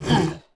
Index of /App/sound/monster/orc_general
damage_1.wav